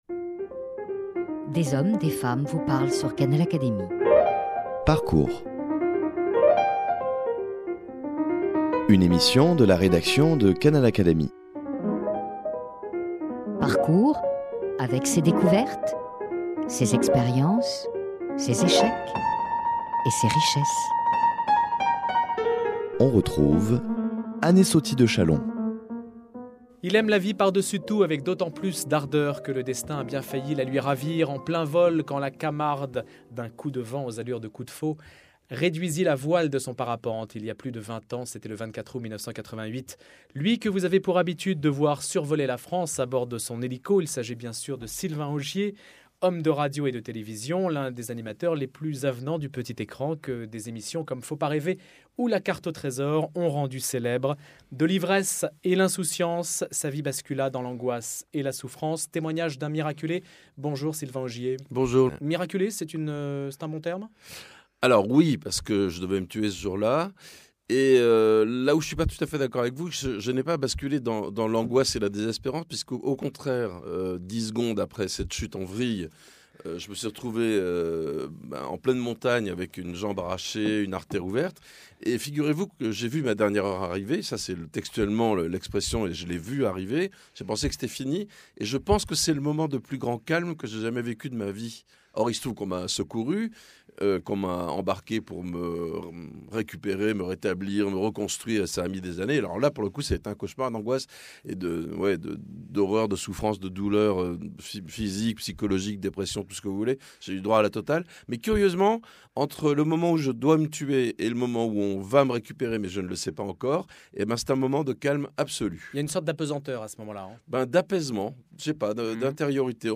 Dans cet entretien, ce natif du sud-ouest raconte cet instant où tout a basculé, quand sa voile s'est mise en torche.